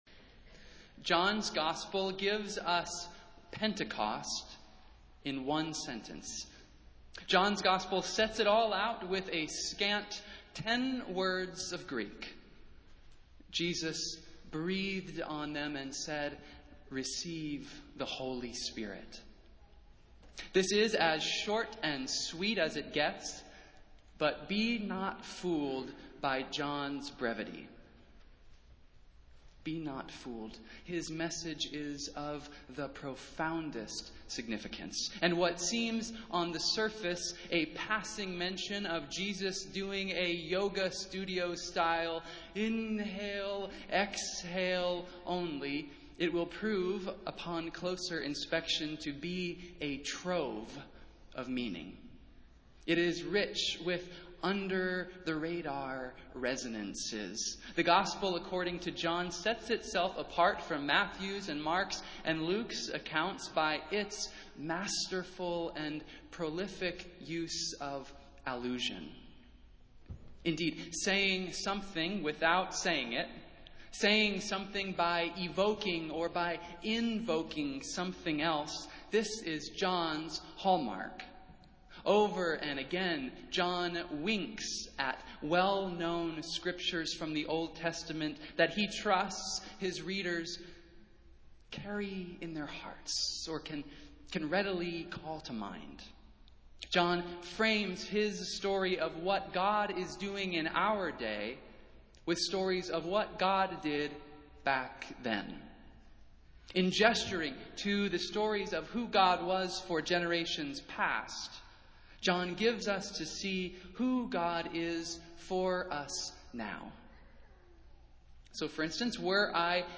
Festival Worship - Second Sunday of Easter